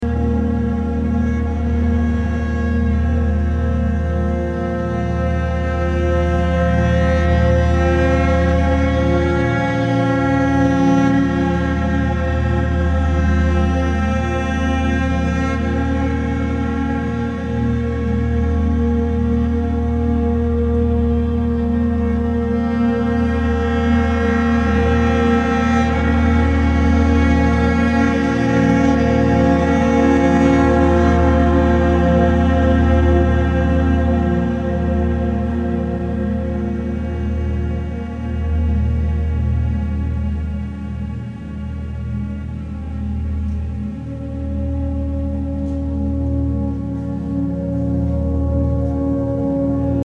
Ambient, Drone >
Post Classical >